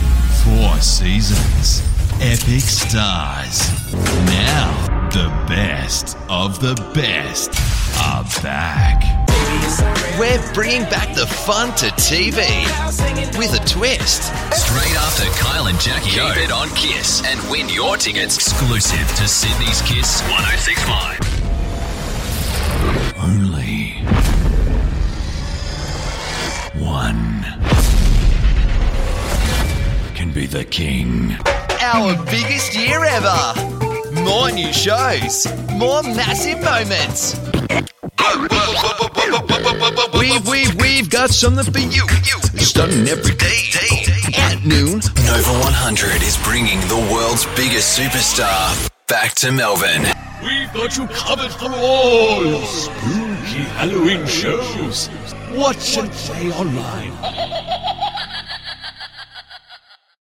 Male
Radio / TV Imaging
Imaging Samples
Words that describe my voice are Australian Accent, Versatile, Professional.